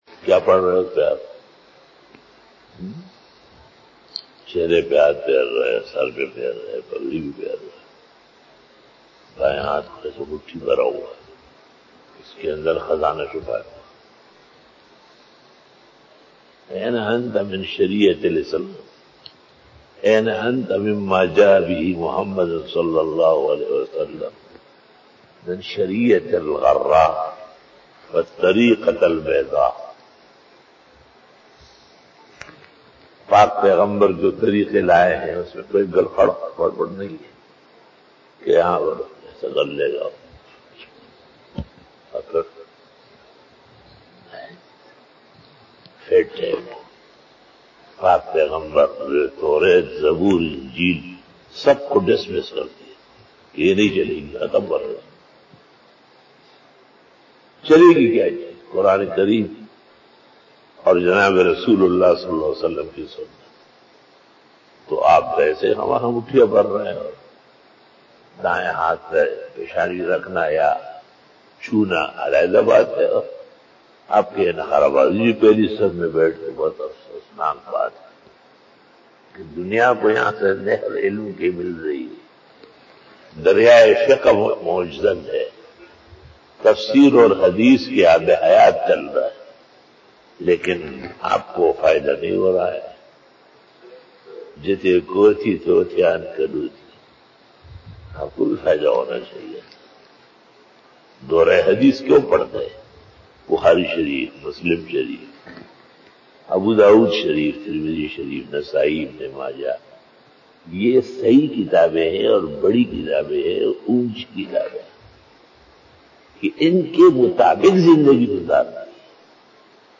Fajar bayan 03 October 2020 ( 15 Safar ul Muzaffar 1442HJ) Saturday
بعد نماز فجر بیان 03 اکتوبر 2020ء بمطابق 15 صفر المظفر 1442ھ بروزہفتہ